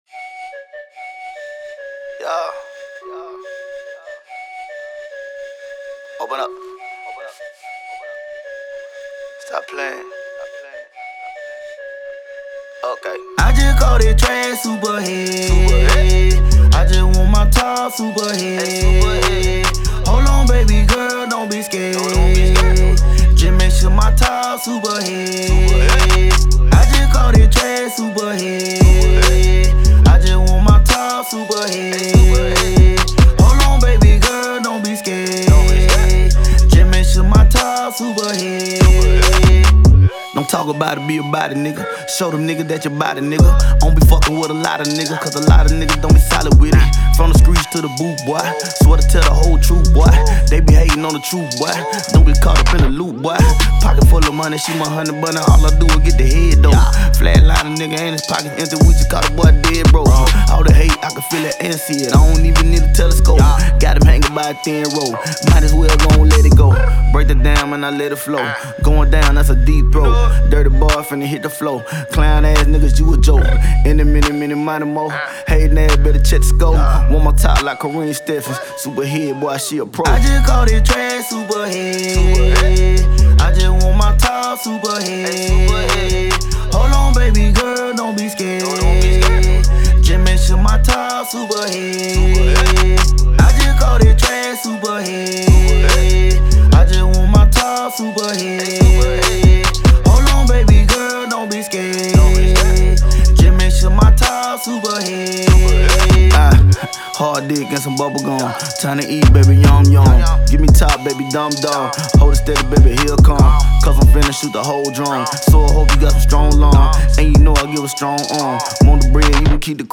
Hiphop
perfect for the summer time vibes!